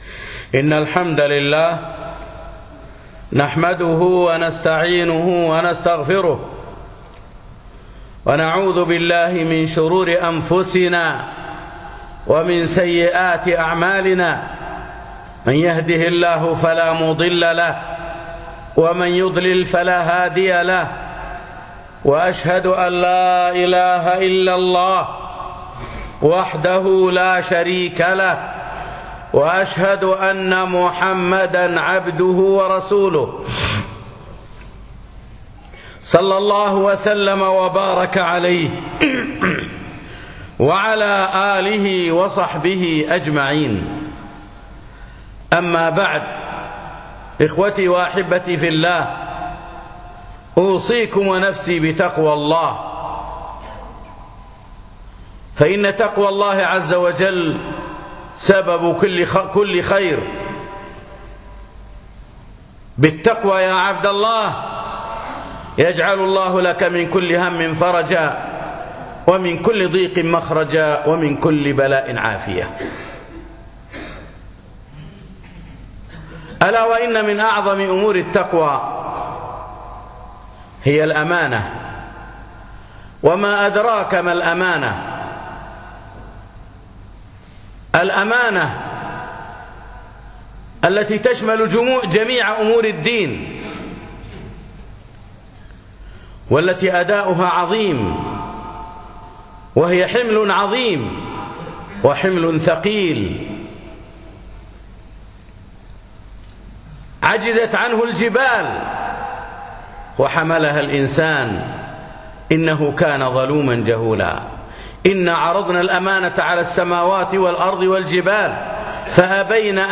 خطبة الأمانة